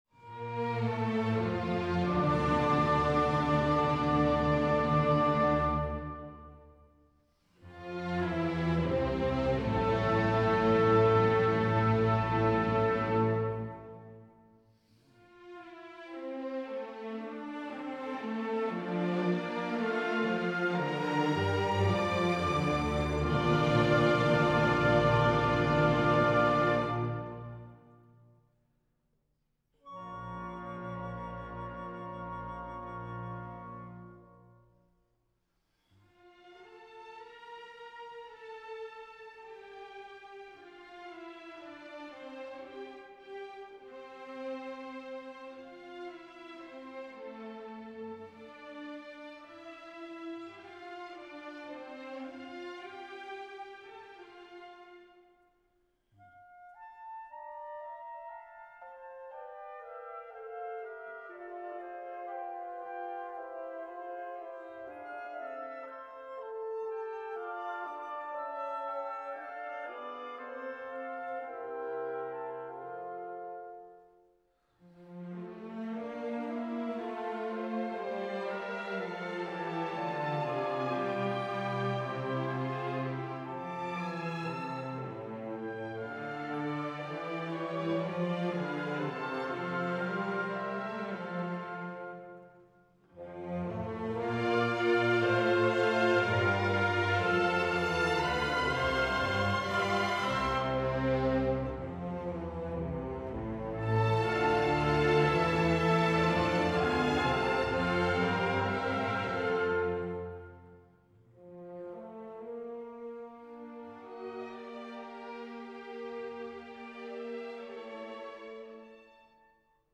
Большой Симфонический Оркестр им. П. Чайковского, 1995 год